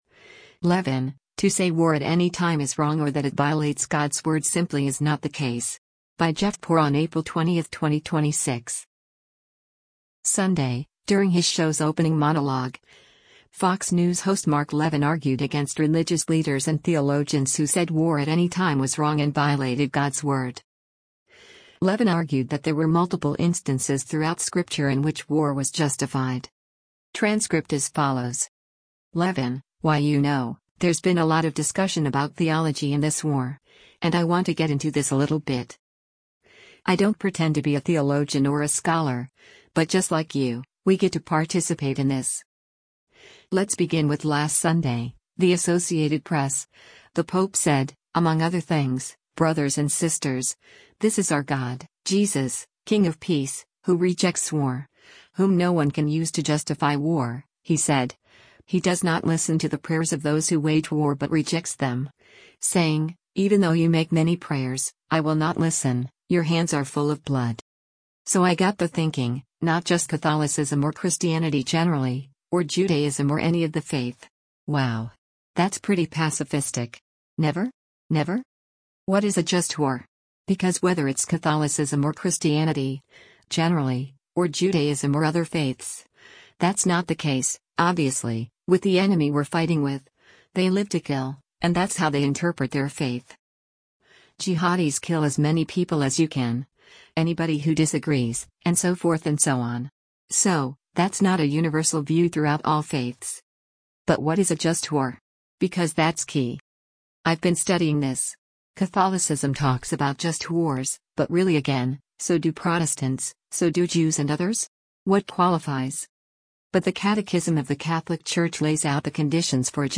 Sunday, during his show’s opening monologue, Fox News host Mark Levin argued against religious leaders and theologians who said war at any time was “wrong” and violated God’s word.